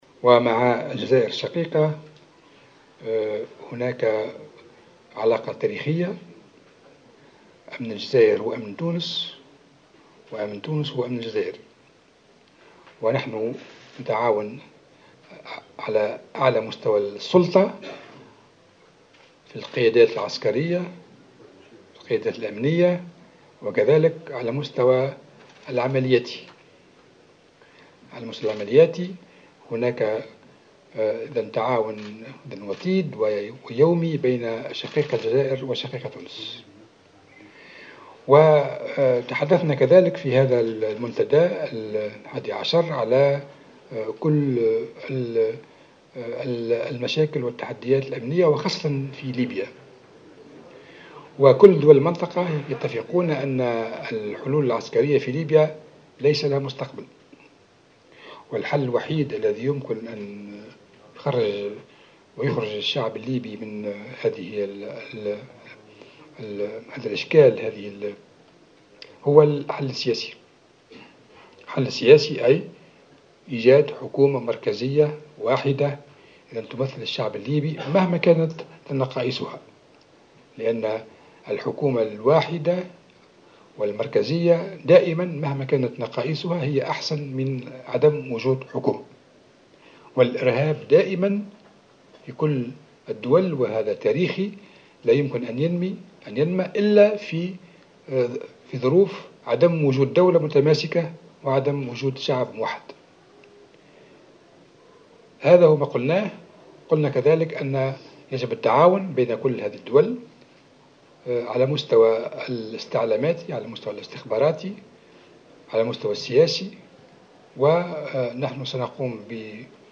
قال وزير الدفاع الوطني، فرحات الحرشاني خلال ندوة بمناسبة اختتام الاجتماع الحادي عشر لوزراء الدفاع إن جل دول المنطقة اتفقوا على أن الحلول العسكرية في ليبيا لا مستقبل لها.